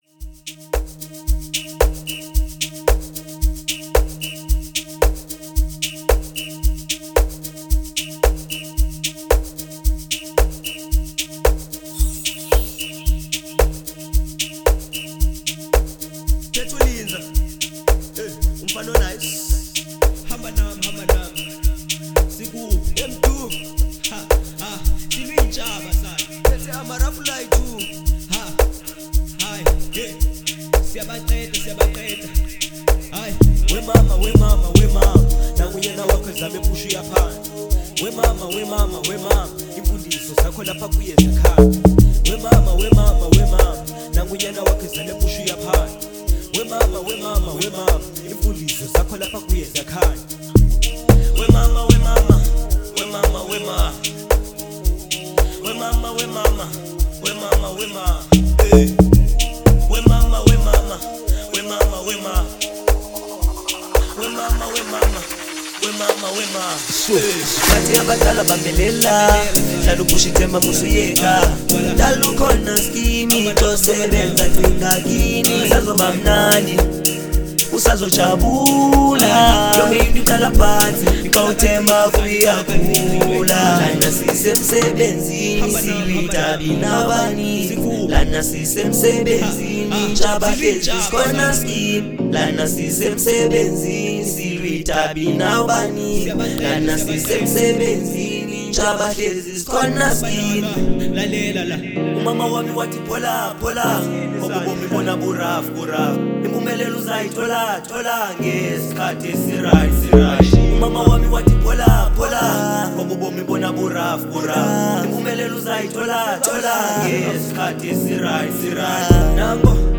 04:33 Genre : Amapiano Size